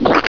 1 channel
splat.wav